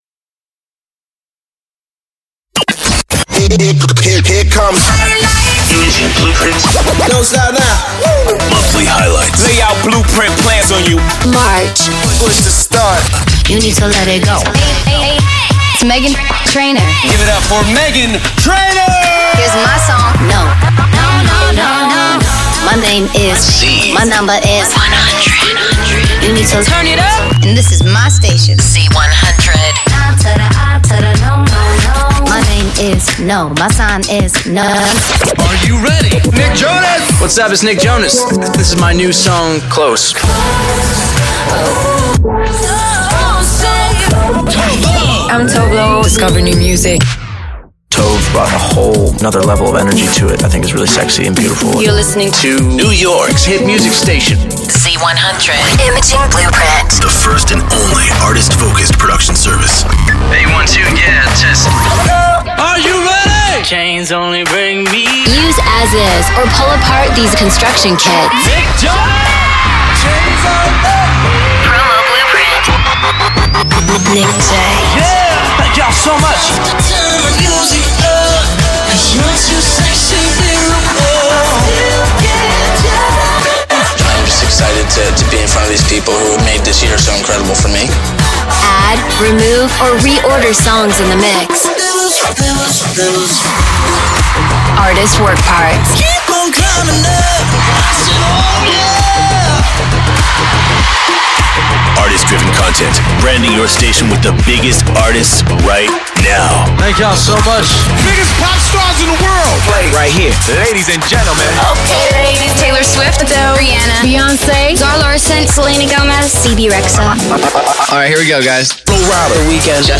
Our clients KIIS & Amp radio are used to demonstrate 'IB' production alongside the world famous Z100.
It features an innovative, online database containing thousands of audio files including sound design, music beds, artist-imaging workparts, vocal work parts, topical elements and much much more!